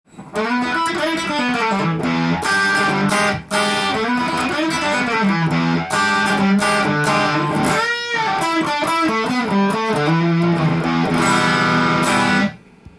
リアで弾くとハムバッカーの太い音がして
安っぽさを感じないギターでした。